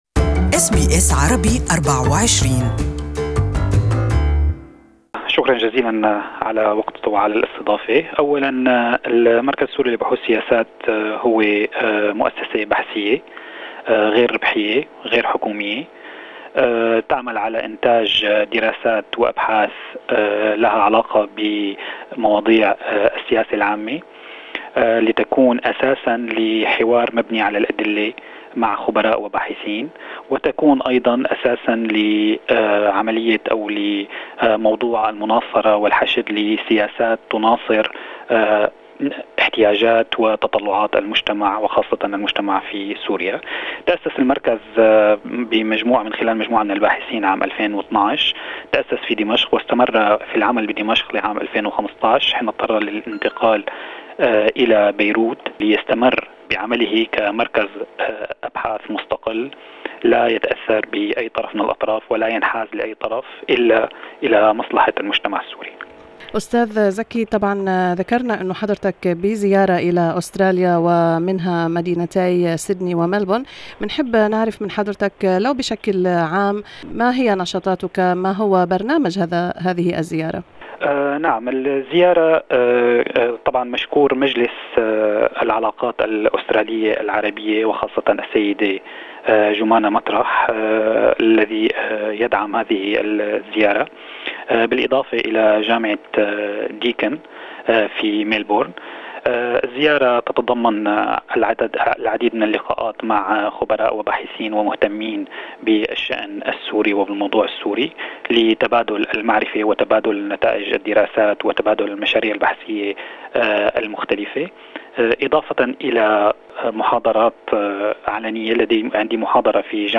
More in this interview in Arabic.